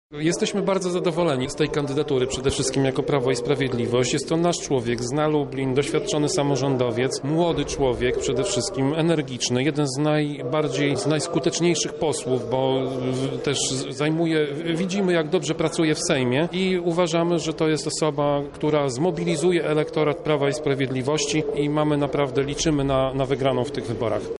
Miejski radny prawa i sprawiedliwości Tomasz Pitucha nie kryje zadowolenia z ogłoszonej przez partie decyzji: